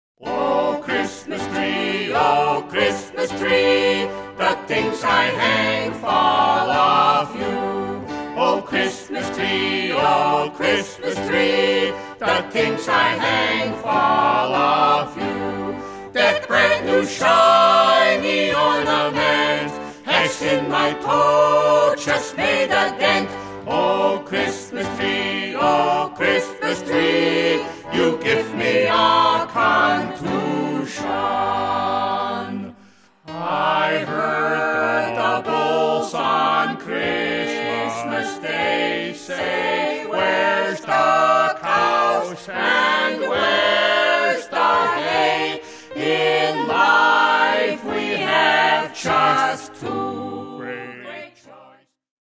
well-recorded and hilarious.